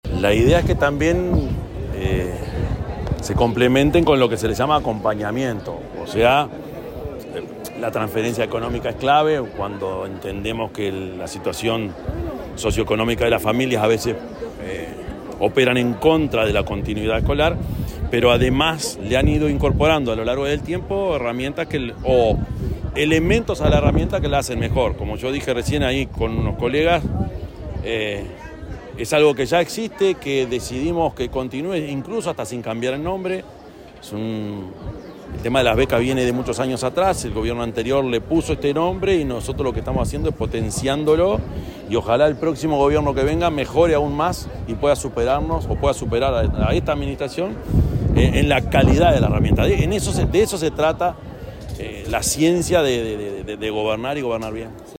Declaraciones del presidente Yamandú Orsi
En declaraciones a la prensa, el presidente Yamandú Orsi subrayó el compromiso del Gobierno para este quinquenio, que prevé ampliar de forma gradual